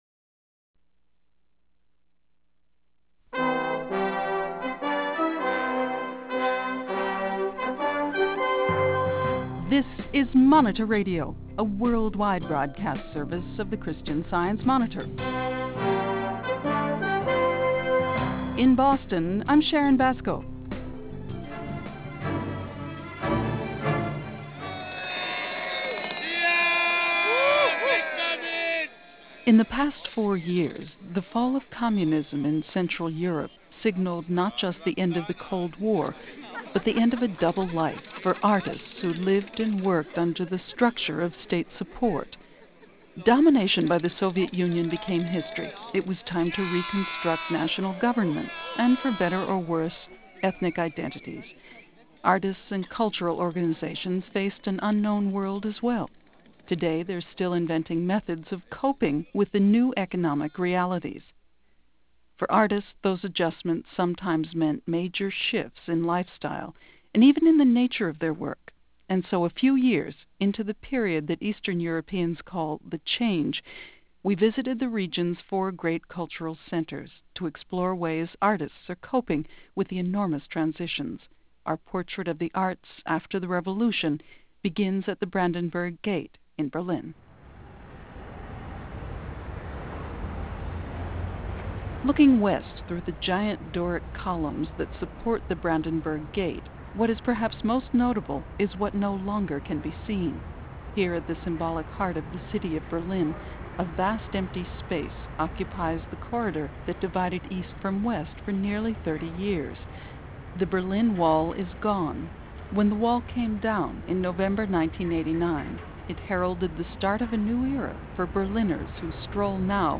The Berlin Wall came down in November of 1989, heralding the start of a new era. Monitor Radio visits the Berlin State opera, the Deutsches Theater, and the Berlin Philharmonic to talk with musicians, actors, and managers who are learning to adjust to the world after socialist funding.